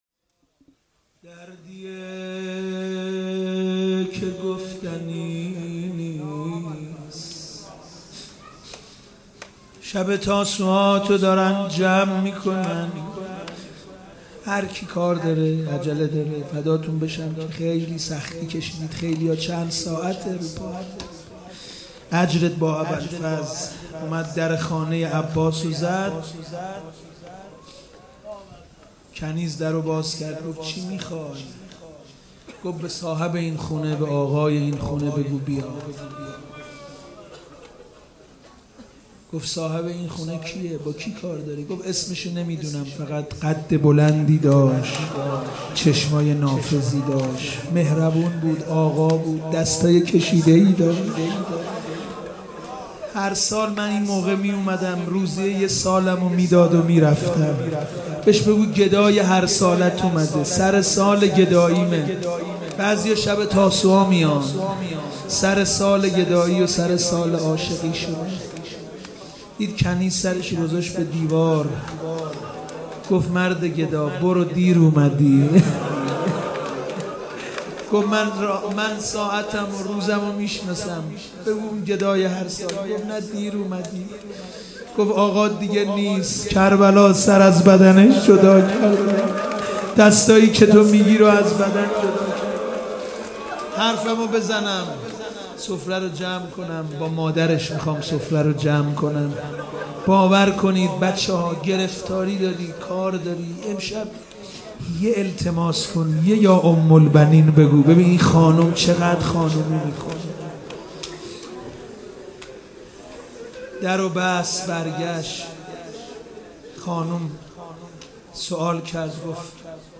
دردیه که گفتنی نیست _ روضه
شب نهم تاسوعا محرم